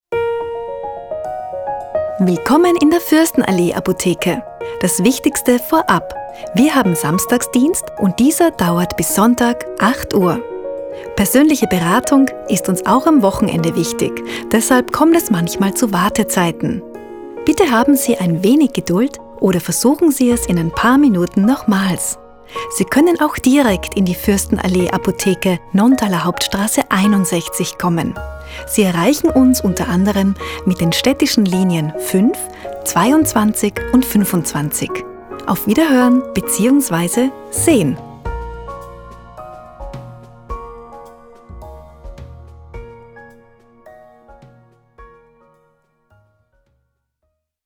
sprecherin
Bei Sprachaufnahmen in Studioqualität geht es – wie bei geschriebenen Texten – darum, den passenden Rhythmus zu finden, den richtigen Ton zu treffen und die gewünschte Stimmung zu transportieren.
Telefon-Anlage: